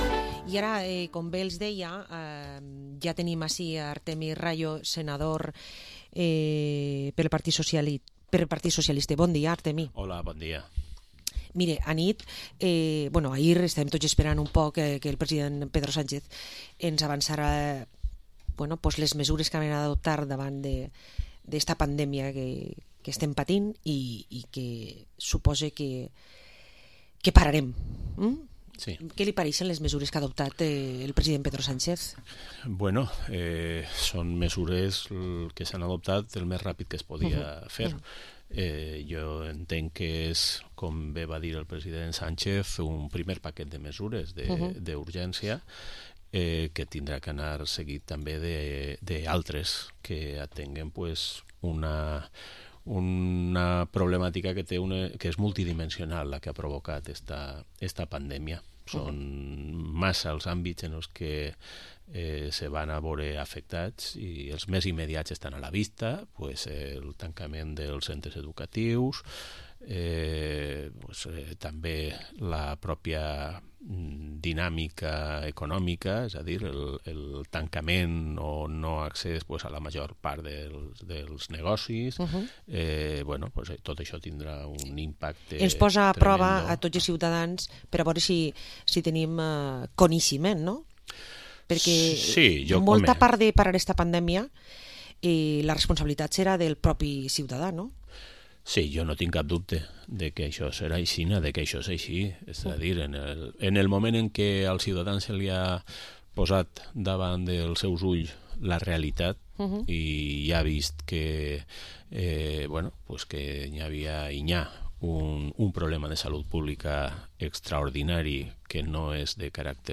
Entrevista al senador del PSPV-PSOE por Castellón, Artemi Rallo